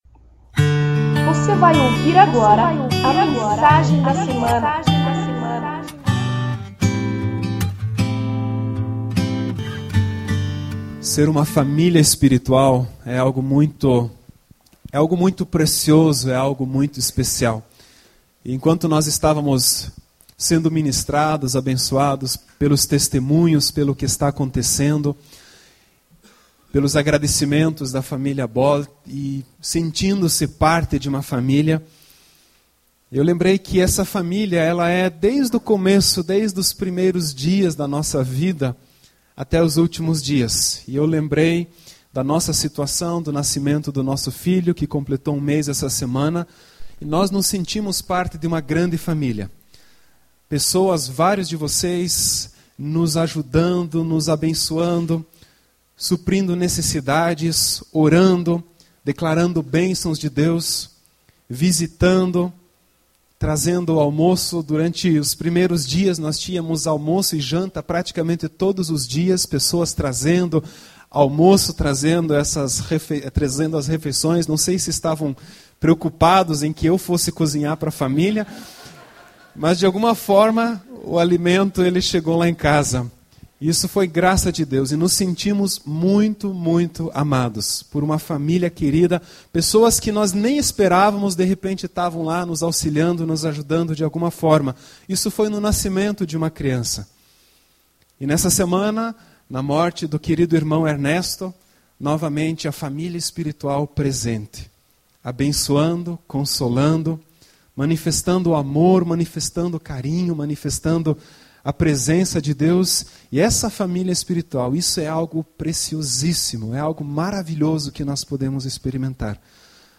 Dez vezes melhor Daniel 1 MENSAGEM · Para àqueles que querem abandonar a ESTAGNAÇÃO espiritual; · Para àqueles que estão SATURADOS do “sistema” falido desse mundo; · Para àqueles que desejam EXERCER A JUSTIÇA do Reino de Deus.